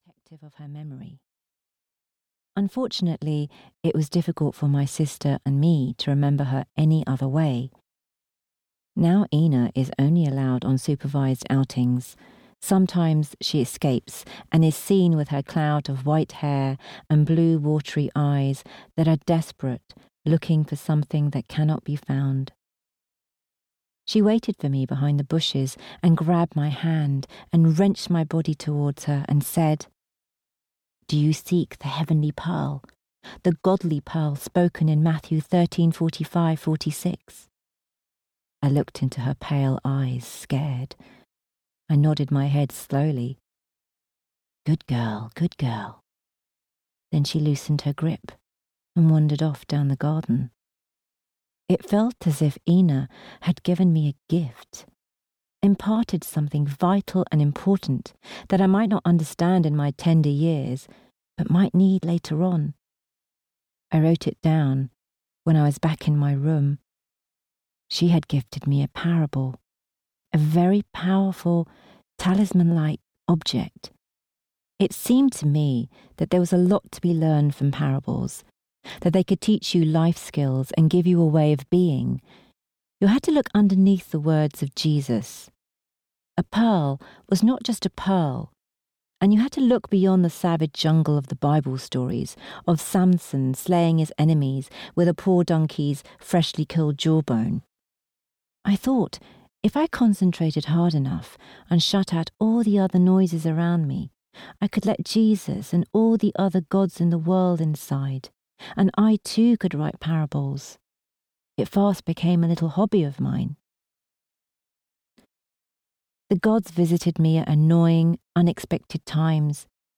Somebody Loves You (EN) audiokniha
Ukázka z knihy